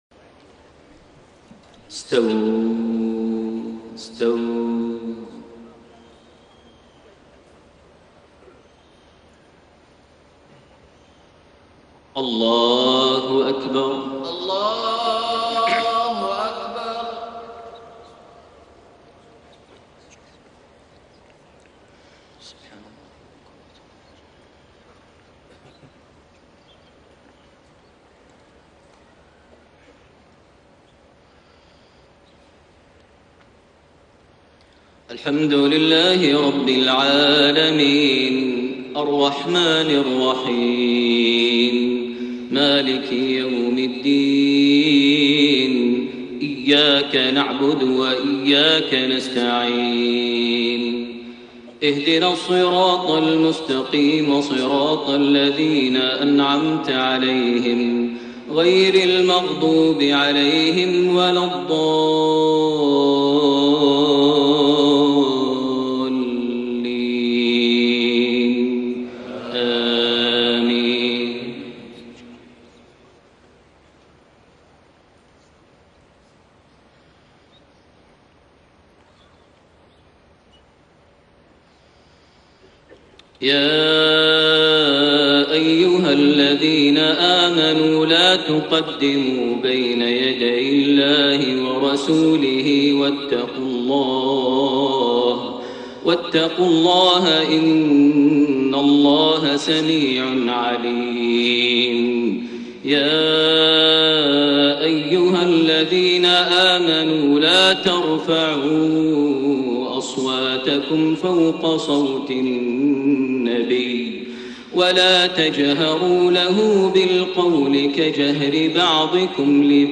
صلاة الفجر 17 رجب 1432هـ | فواتح سورة الحجرات 1-13 > 1432 هـ > الفروض - تلاوات ماهر المعيقلي